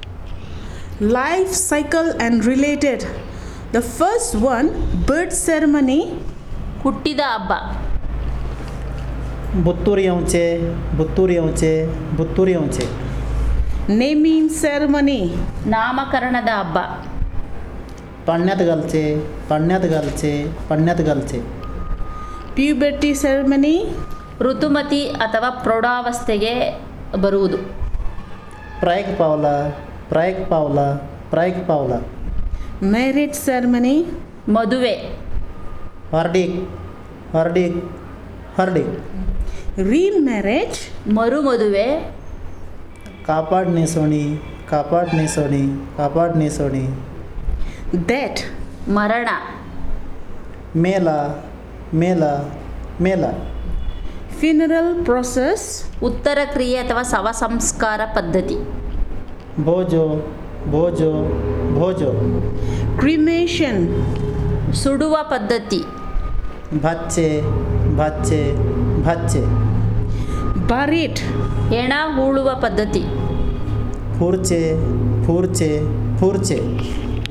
Elicitation of words about life cycle and related